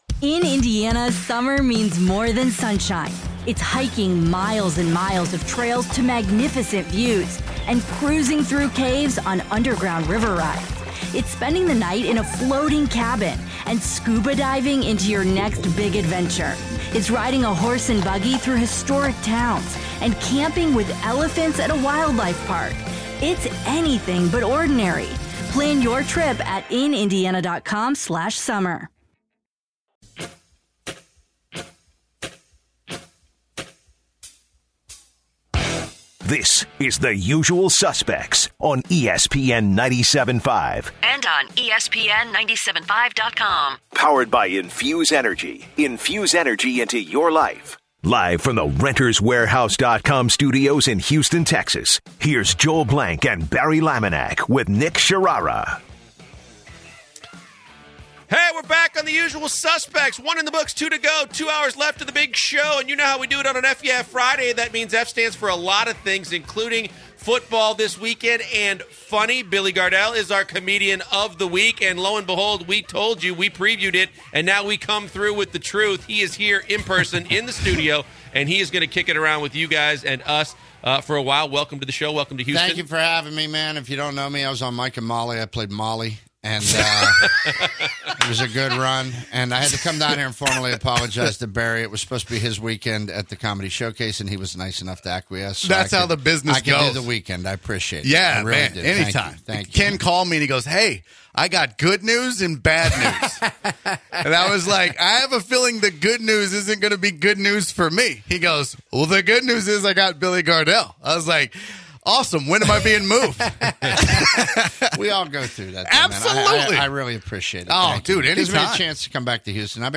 In the second hour of the show, the guys are joined in the studio by comedian and actor Billy Gardell, one of the stars from the CBS show Mike & Molly to discuss the NFL Playoffs, the Super Bowl, what he’s doing now, and tell some hilarious stories. As the hour continues, they continue the Super Bowl discussion and they finish it out by bringing in a couple interns to compete in an eating contest for some air time to give their Super Bowl pick.